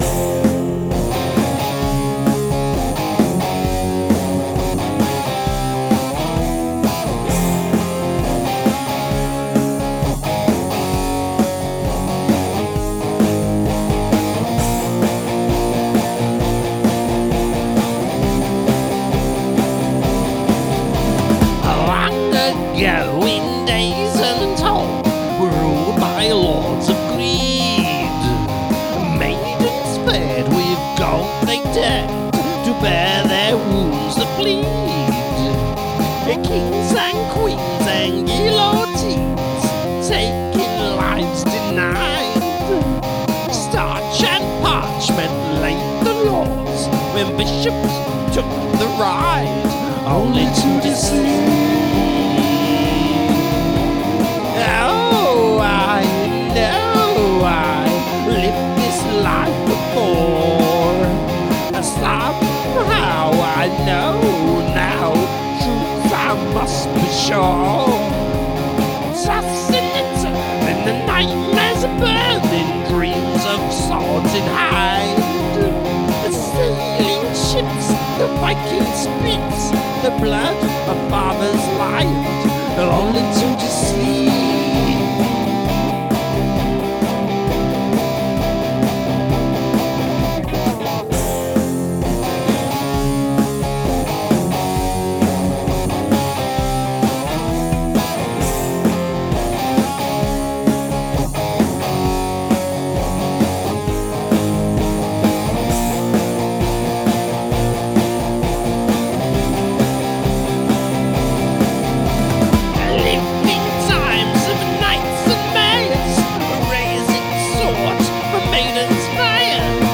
Prominent 'yapping' removed due to sounding silly